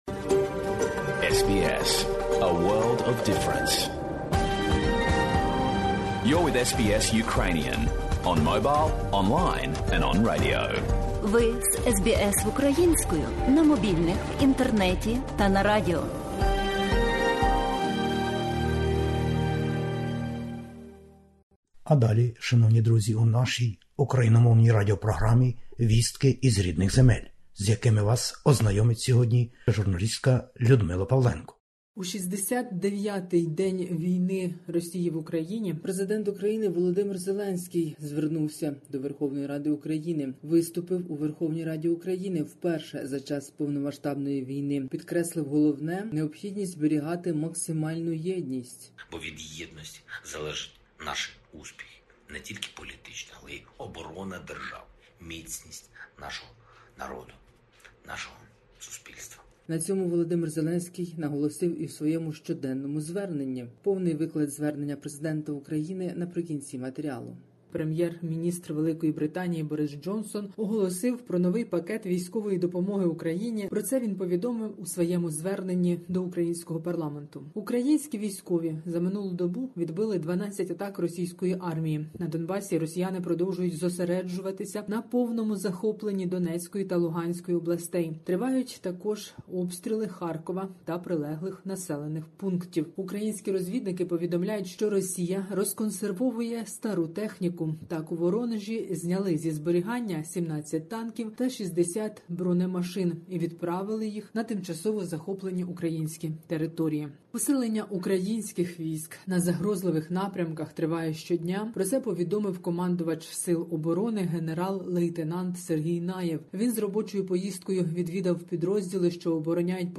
Добірка новин із воюючої України-героїні. Президент України виступив перед парламентарями.